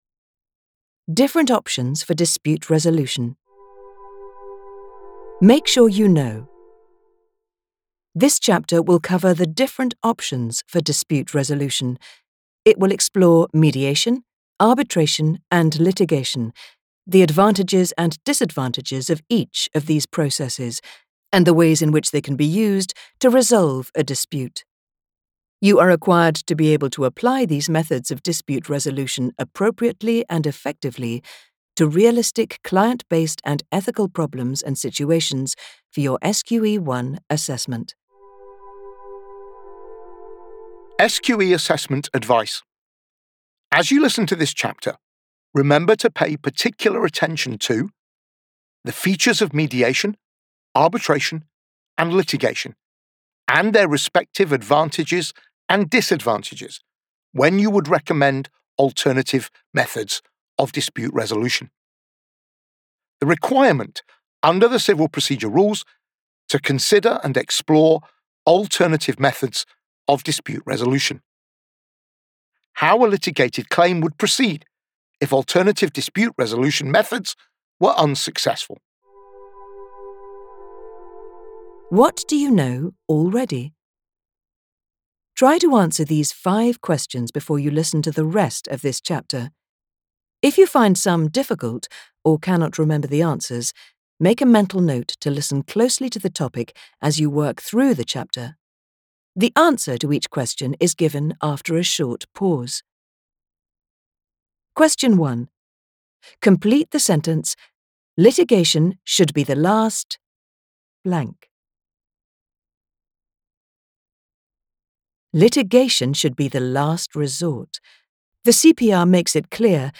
Audiobook Sample Free revision checklist